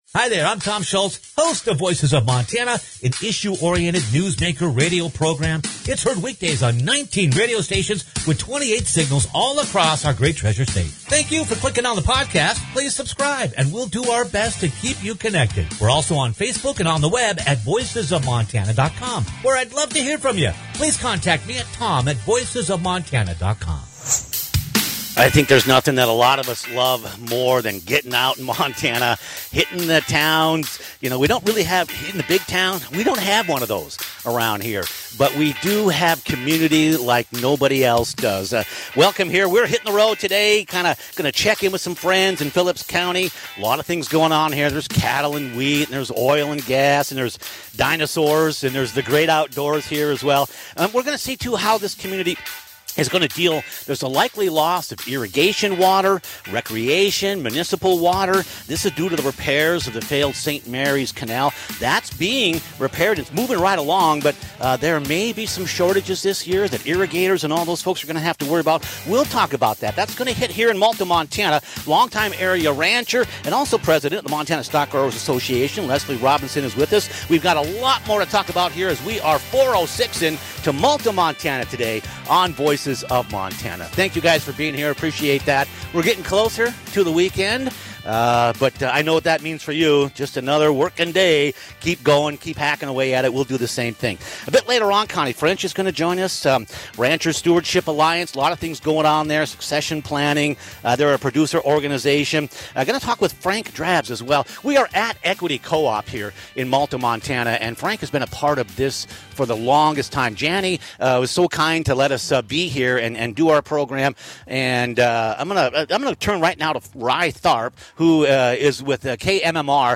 Irrigation, Recreation & Municipal Water on the Hi-Line – LIVE - Voices of Montana